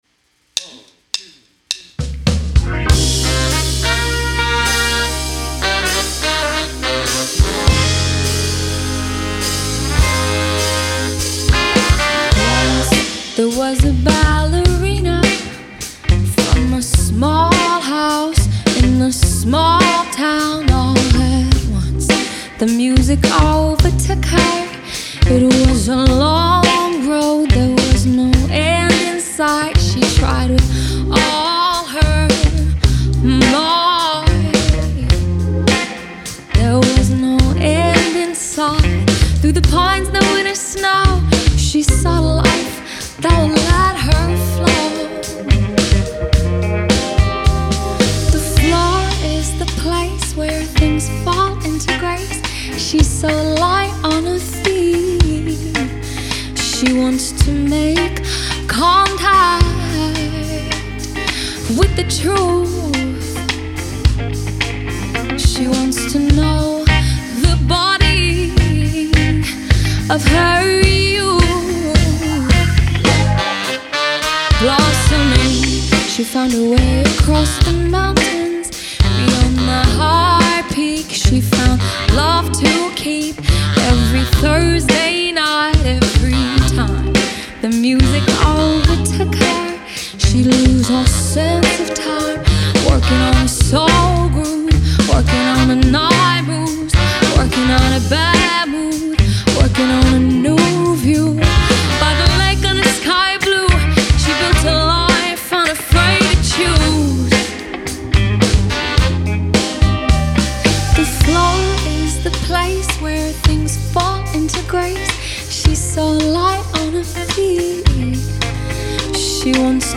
ich finde, die stimme ist im raum zu weit hinten, klingt phasig und etwas dumpf. außerdem scheinen mir insgesamt die low mids etwas stark repräsentiert und leicht breiig zu sein.